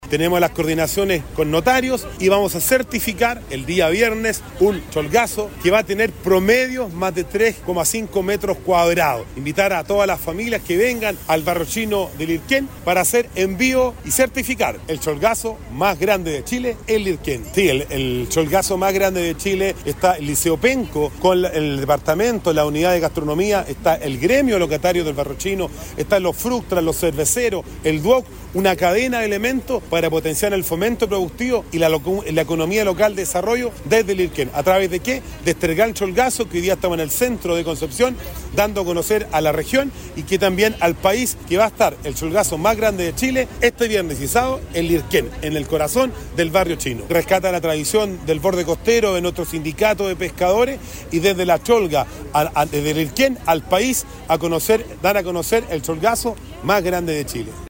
El alcalde de Penco, Rodrigo Vera, destacó el trabajo conjunto entre gremios, instituciones y la comunidad.